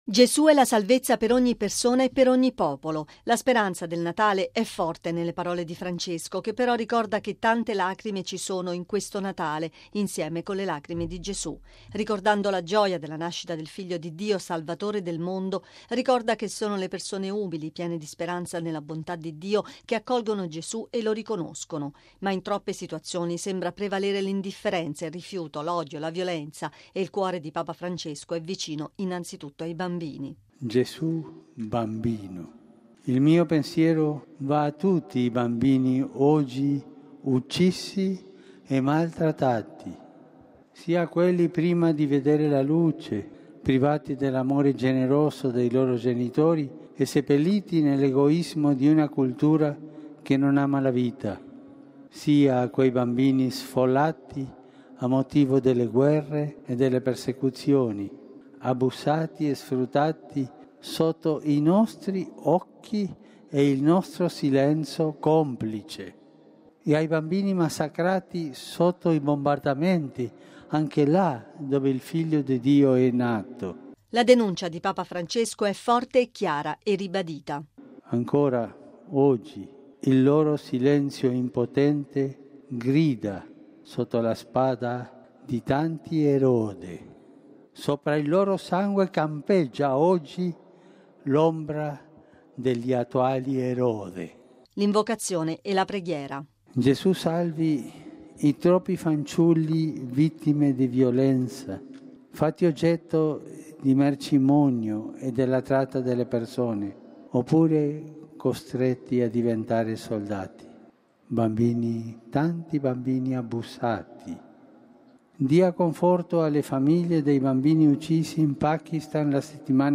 Il Papa parla a oltre 80.000 persone raccolte in Piazza San Pietro.